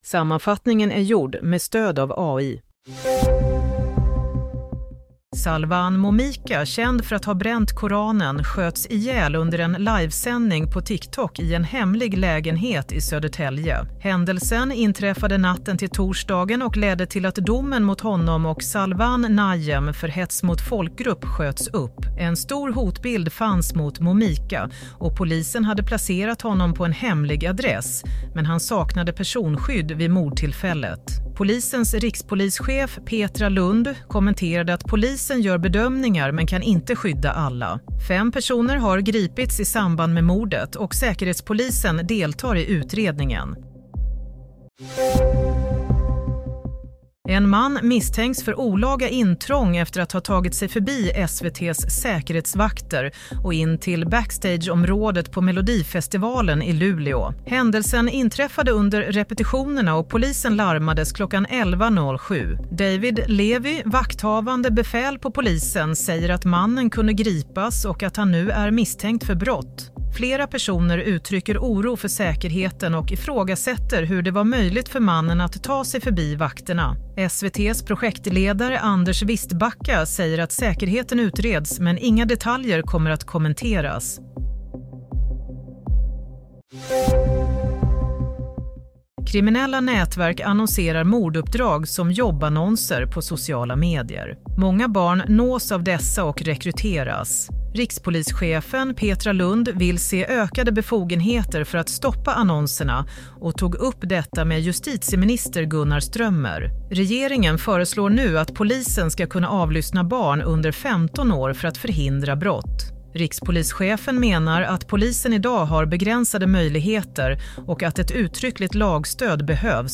Nyhetssammanfattning – 31 januari 07:00